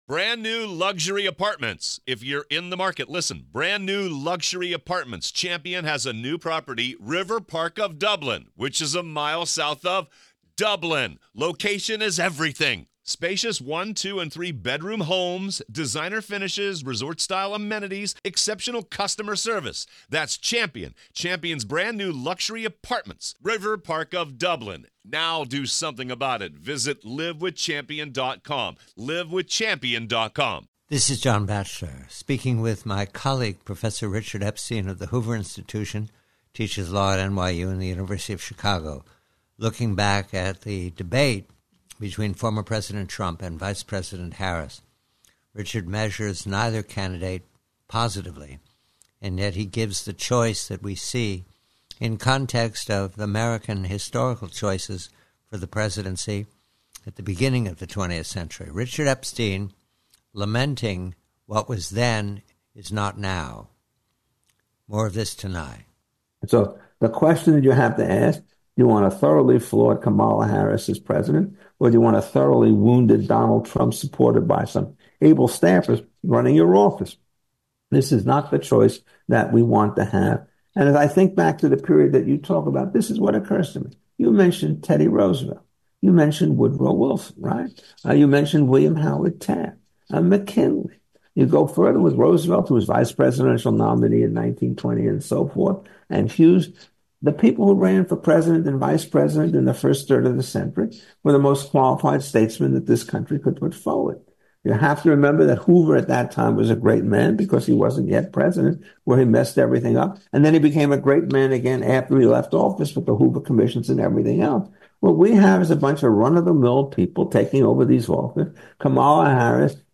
PREVIEW: POTUS DEBATE: THE ROOSEVELTS: Conversation with colleague Professor Richard Epstein of the Hoover Institution re the admirable statesmen of the presidential successes in the early 20th Century in comparison to our present choices.